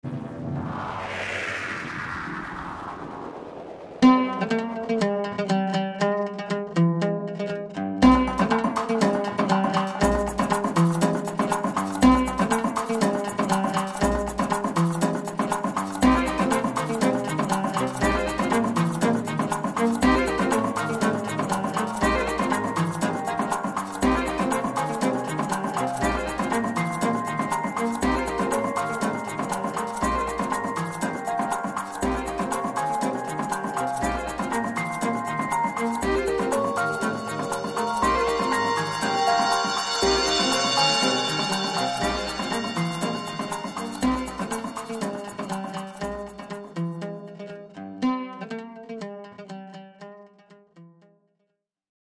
If I recall correctly, I threatened to post some of my GarageBand stuff a while ago and never got around to it…
Think of it as part of some background music from the soundtrack to a movie that doesn’t yet exist.